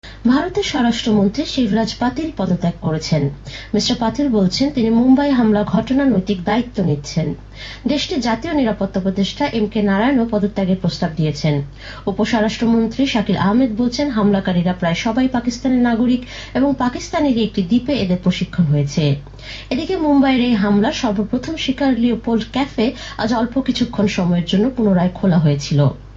Here’s part of a news report in a mystery language. Do you know or can you guess which language it is?